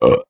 burp_1.ogg